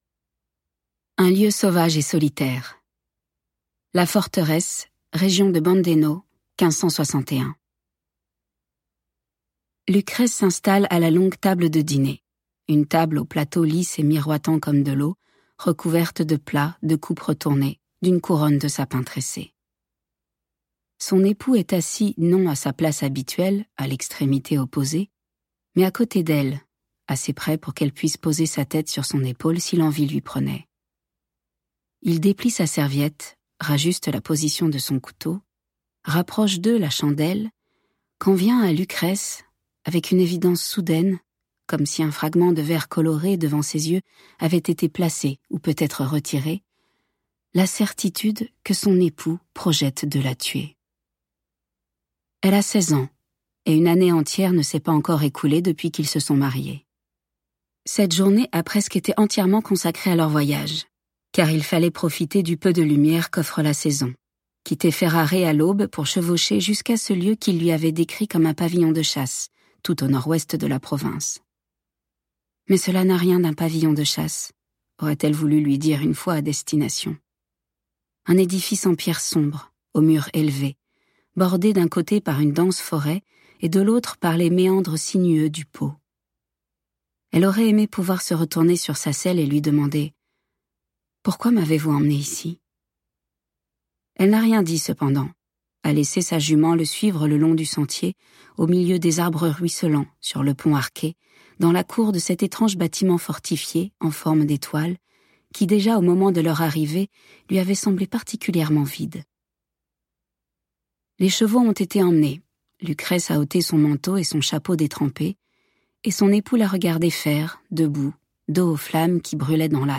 Extrait gratuit - Le Portrait de mariage de Maggie O'FARRELL